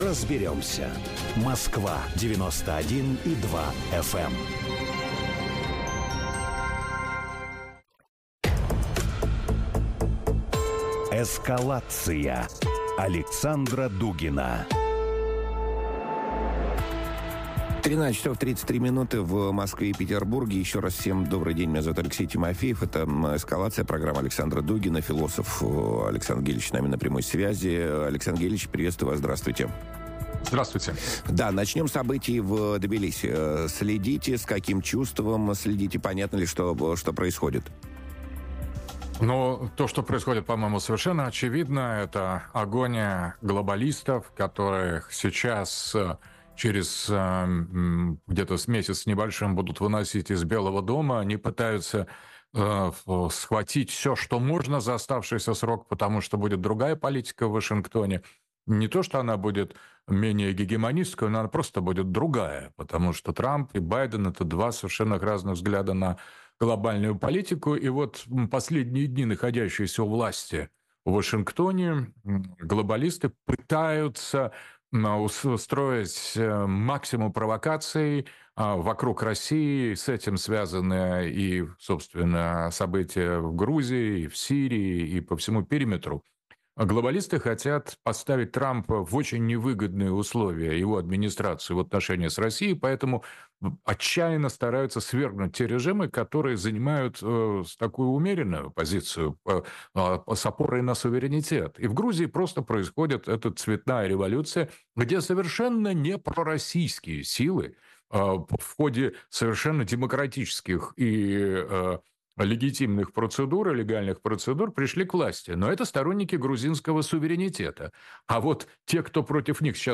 Ответы на эти и другие вопросы ищем в эфире радио Sputnik с философом Александром Дугиным.